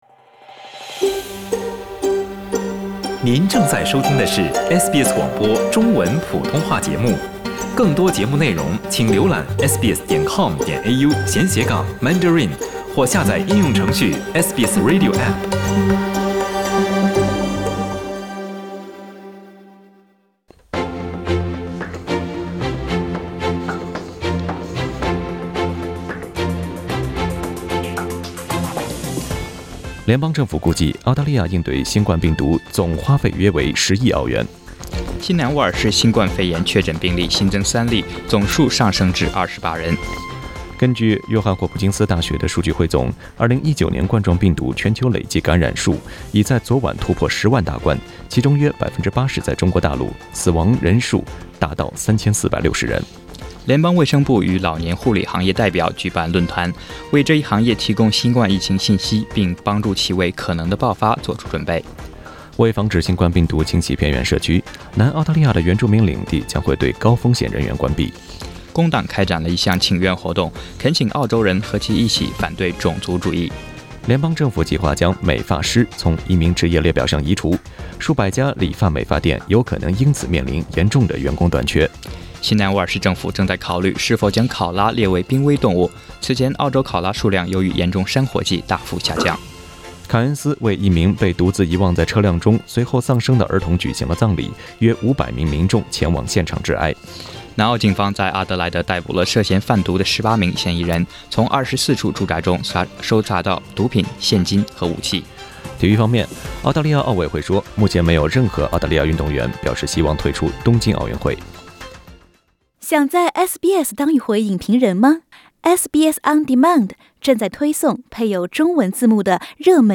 SBS 早新闻 （3月7日）